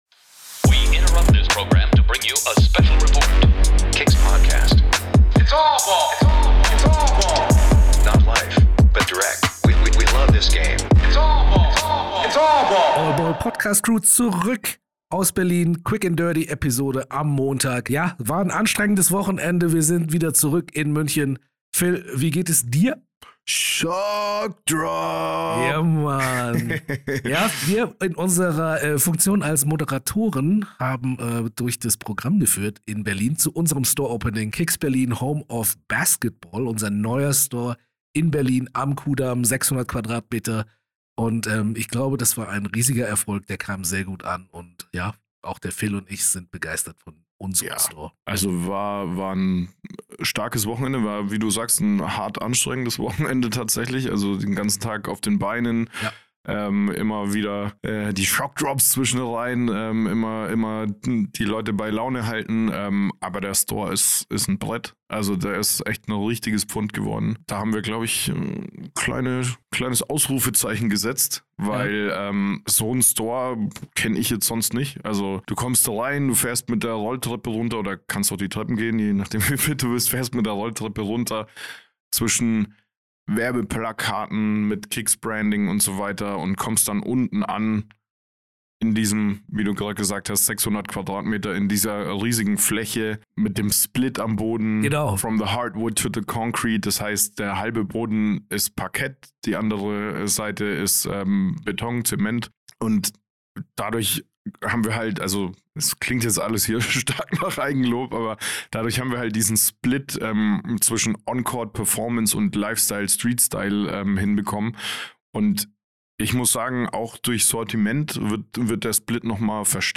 Es waren noch so viel mehr Leute vor Ort, die wir gerne noch kurz für unsere Hörer interviewt hätten, aber das war wegen unserer Doppelfunktion als Moderatoren dann teilweise schwierig. Shoutout an alle, die vorbeigeschaut haben!